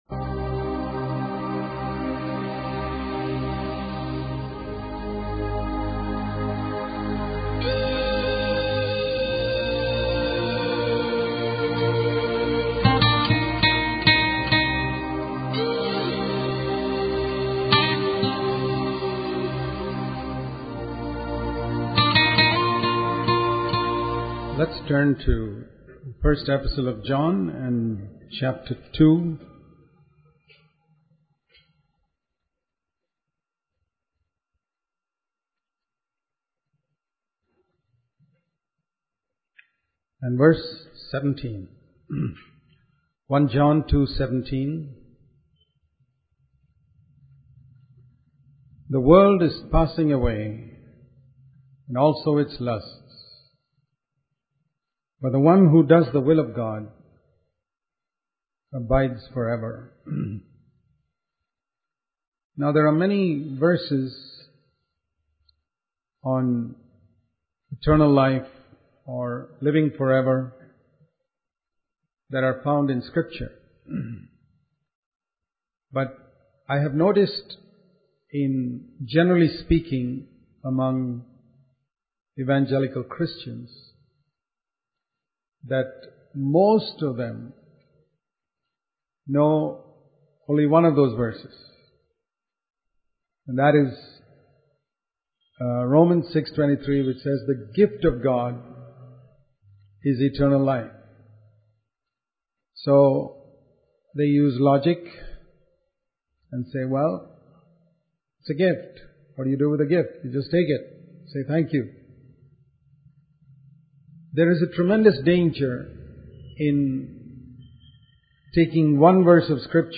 In this sermon, the preacher emphasizes the importance of understanding and doing the will of God. He refers to the Sermon on the Mount in Matthew 5, 6, and 7 as a revelation of the will of God for believers in the new covenant.